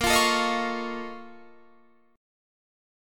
A#mM11 Chord
Listen to A#mM11 strummed